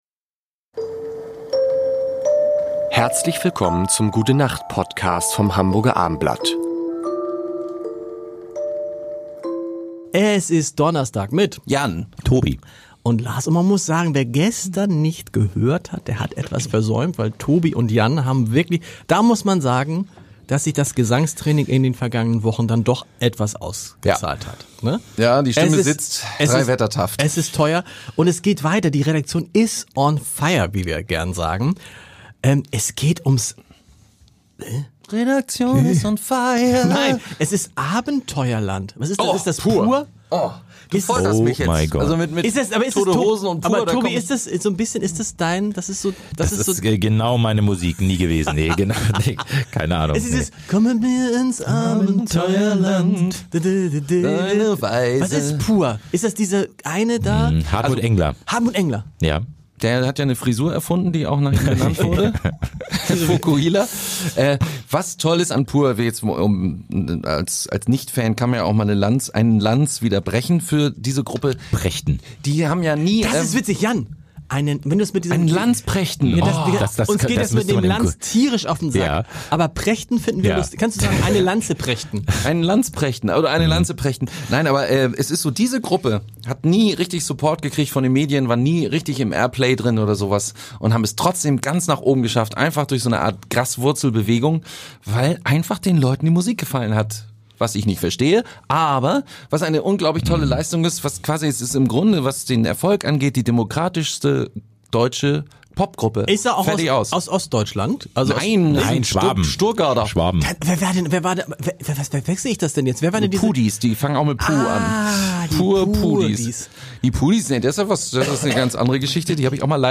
Ein pures Musikvergnügen, aber nicht für jeden.